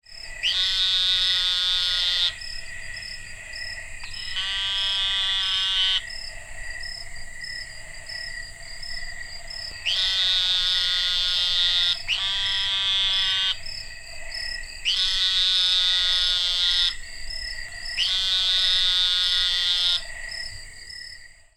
Sinaloan Narrow-mouthed Toad - Gastrophryne mazatlanensis
Advertisement Calls
It is produced by a male frog in order to attract females during the breeding season and to warn other rival males of his presence.
Sound  This is a 21 second recording of the advertisement calls of several Sinaloan Narrow-mouthed Toads calling at a close distance from a small pool in a creek in Santa Cruz County, Arizona in August at night. Insects can be heard in the background.
gastrophrynecalls.mp3